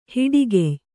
♪ hiḍigey